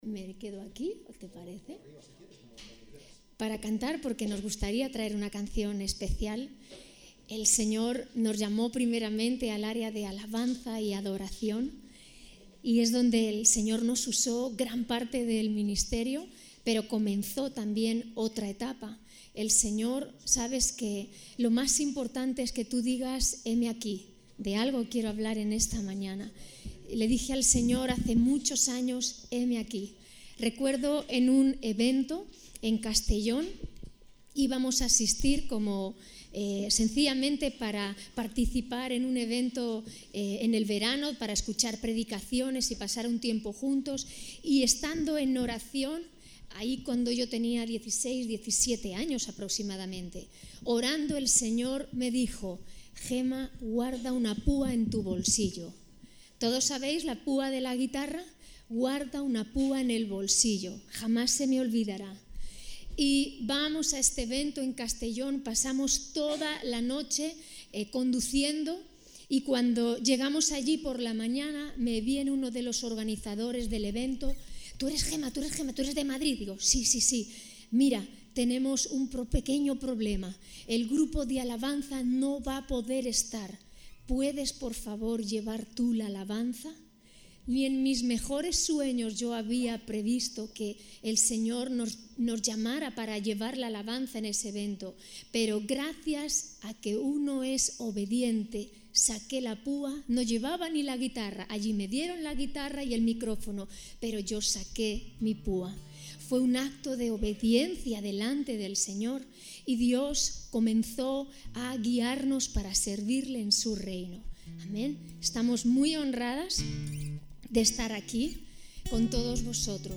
Predicaciones